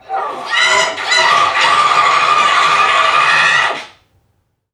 NPC_Creatures_Vocalisations_Robothead [80].wav